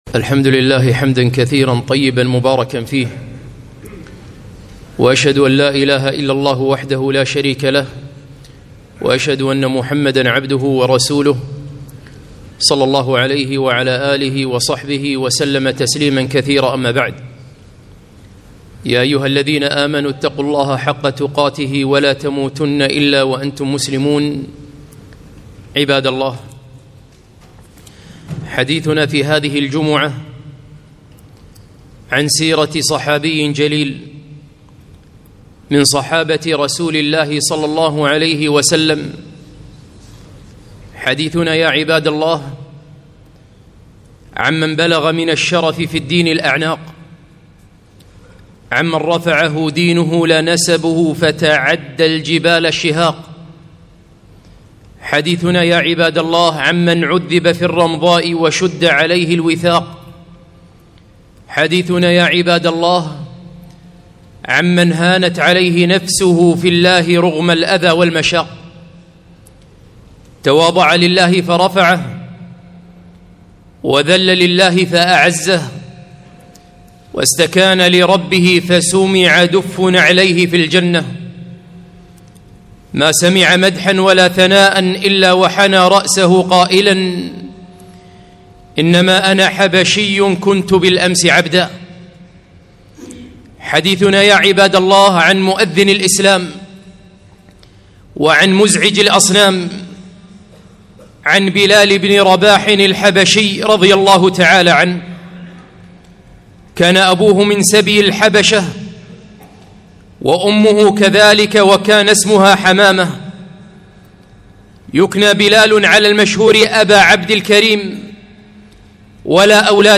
خطبة - مؤذن الرسول بلال بن رباح